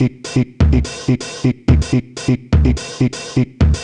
cch_jack_percussion_loop_low_125.wav